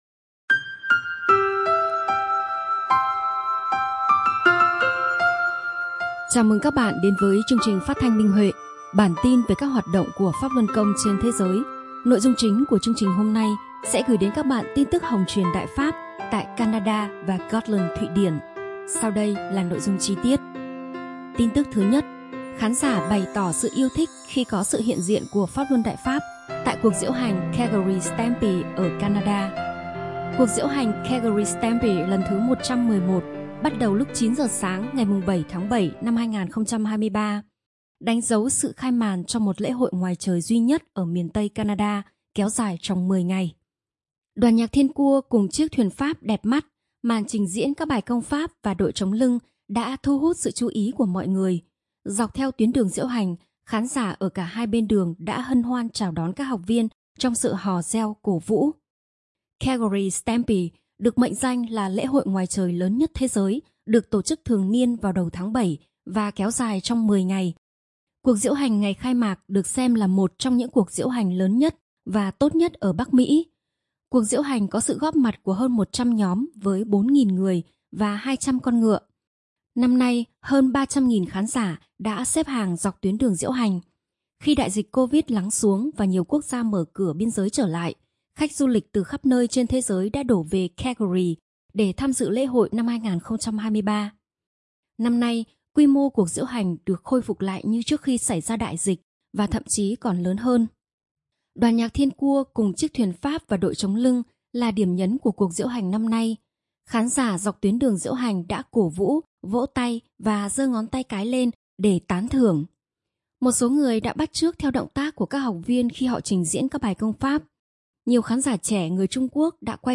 Chương trình phát thanh số 45: Tin tức Pháp Luân Đại Pháp trên thế giới – Ngày 14/7/2023